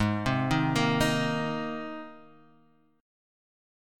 G#add9 chord